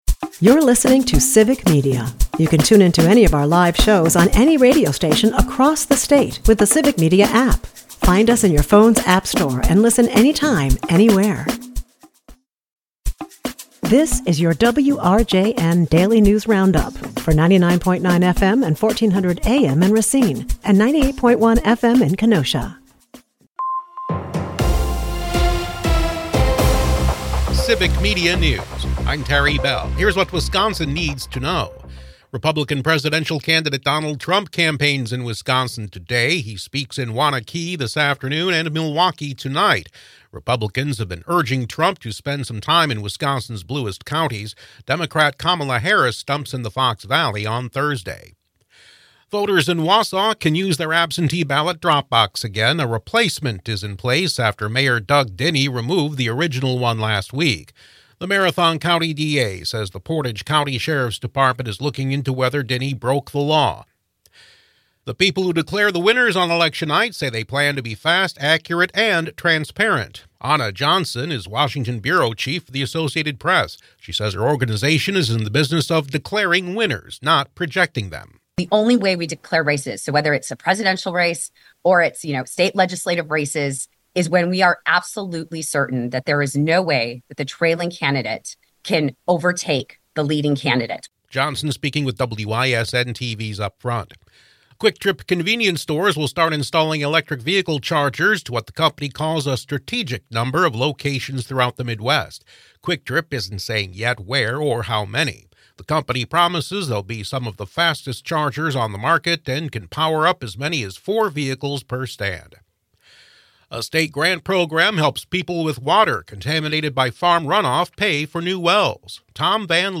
wrjn news